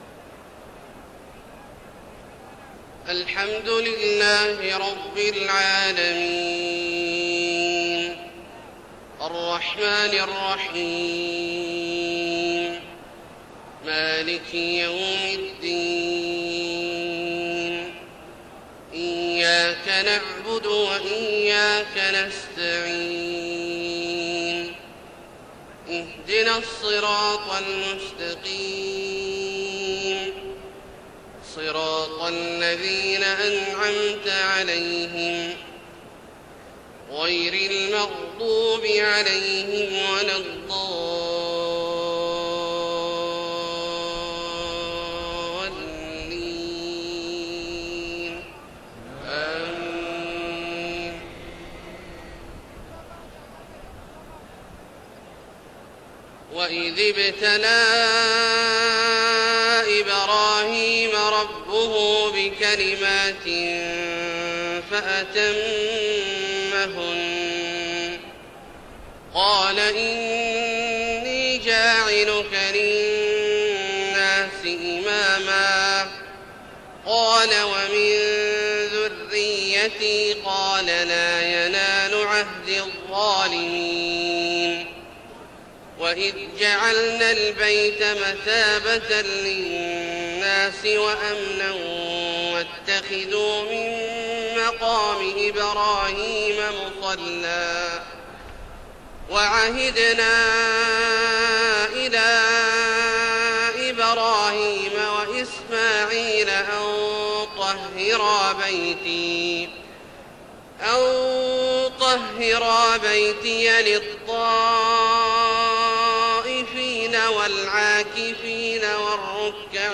فجر 9-4-1432هـ من سورة البقرة {124-141} > ١٤٣٢ هـ > الفروض - تلاوات عبدالله الجهني